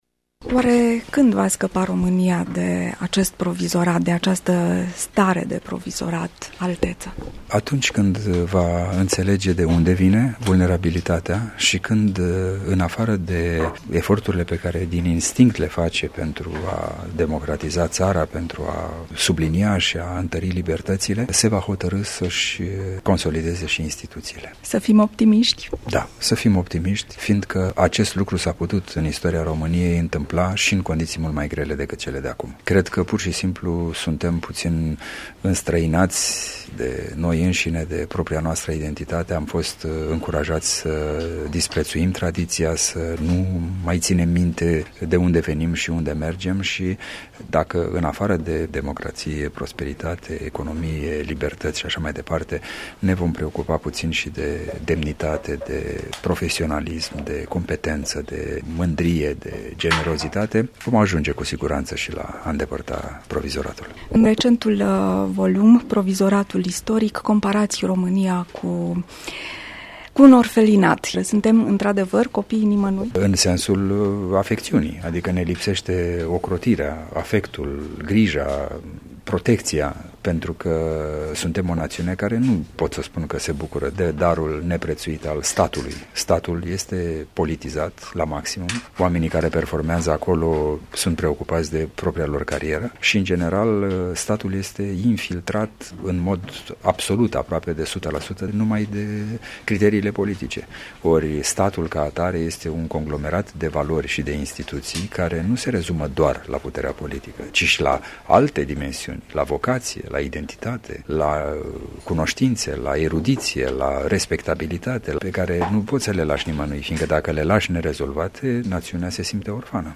Un interviu
Radio-88-De-vb-cu-Principele-Radu.mp3